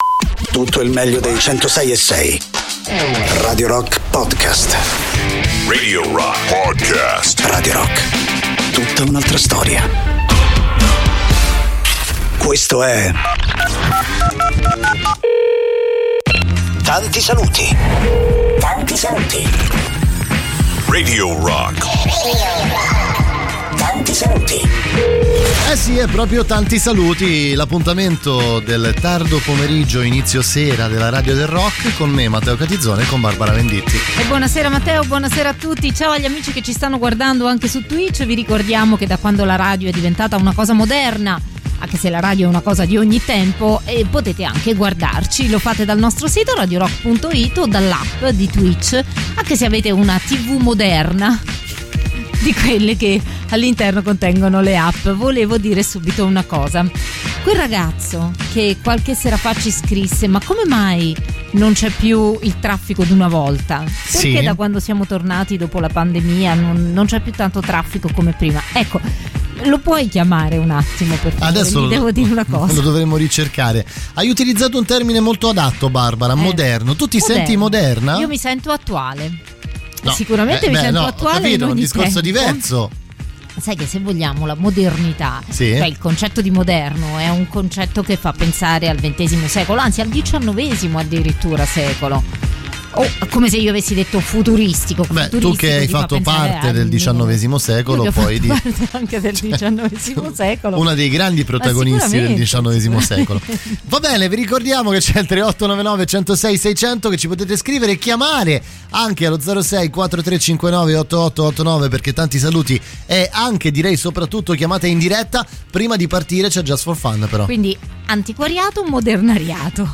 in diretta dal lunedì al venerdì, dalle 19 alle 21